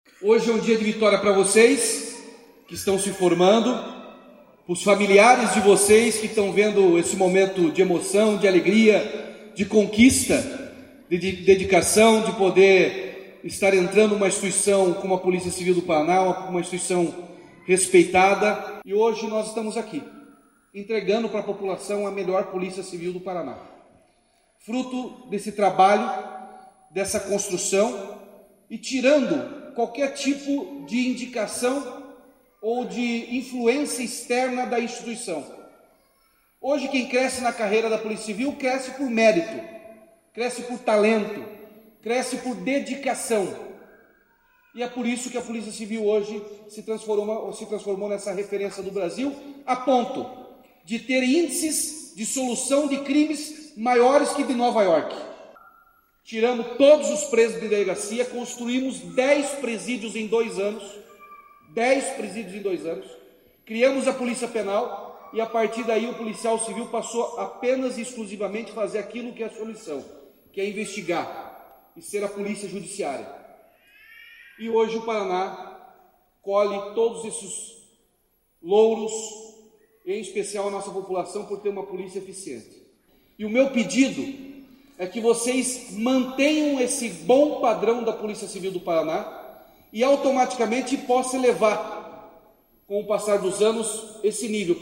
Sonora do governador Ratinho Junior sobre a formatura de 555 novos integrantes da PCPR